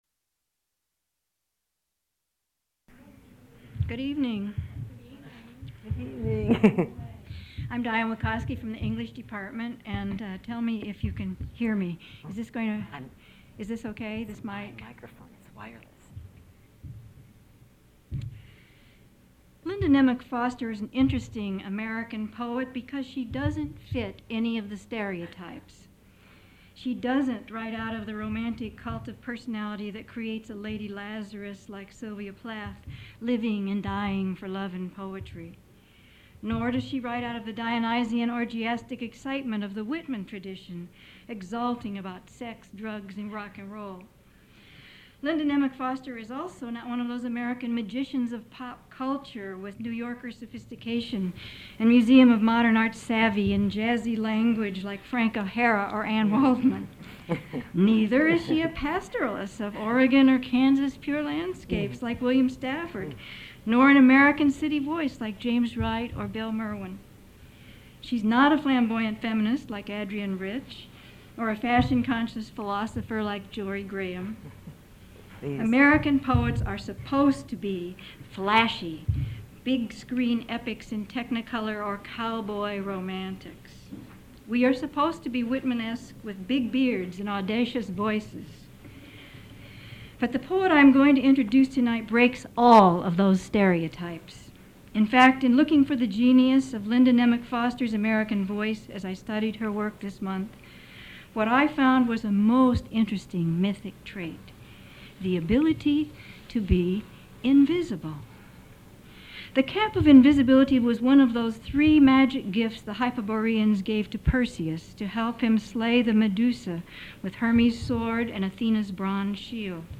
Program introduction by Diane Wakoski.
Recorded at the Michigan State University Libraries by the Vincent Voice Library on Mar. 26, 1999.